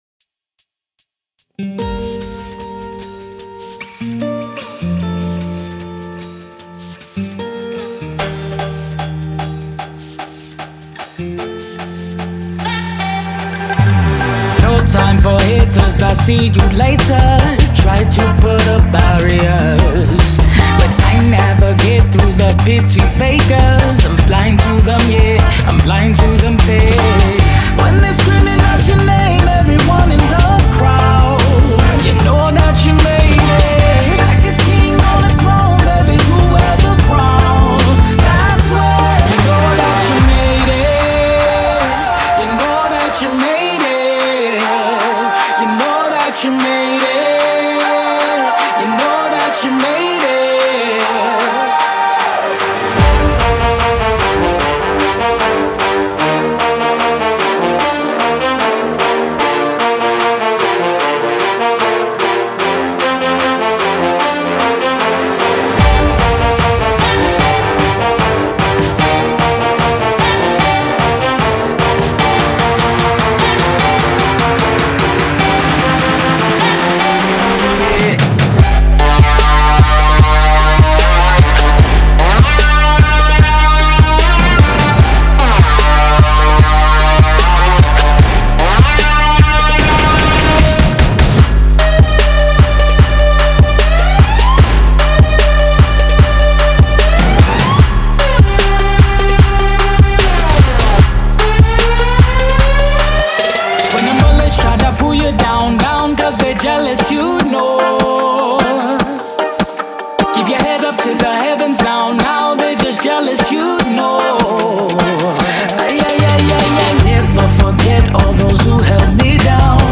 • Жанр: Electronic, EDM, House